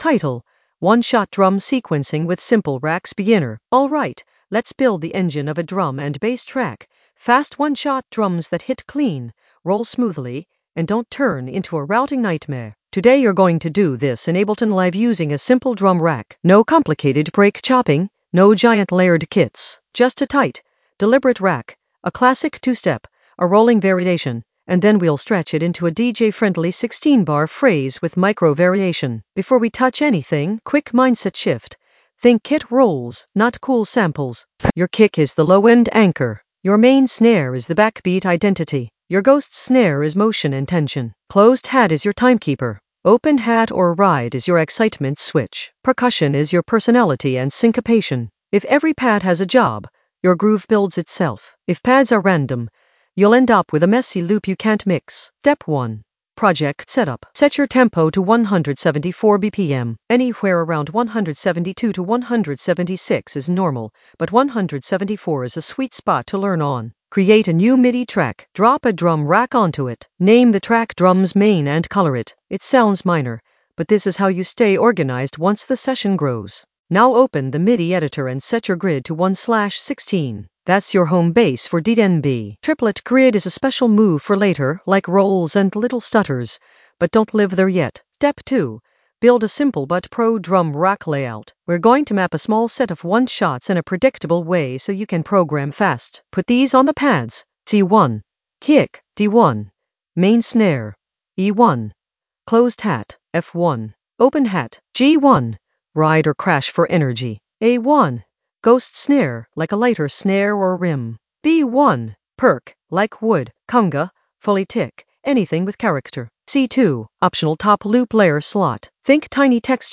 An AI-generated beginner Ableton lesson focused on One-shot drum sequencing with simple racks in the Drums area of drum and bass production.
Narrated lesson audio
The voice track includes the tutorial plus extra teacher commentary.